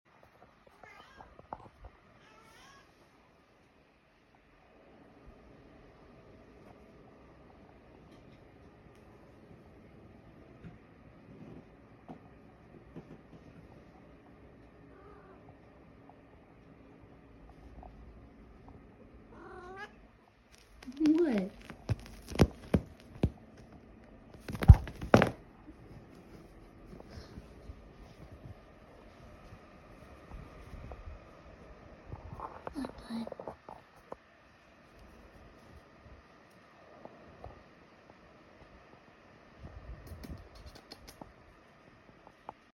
Volume up on loud to hear Tangerine meow “hello” 😹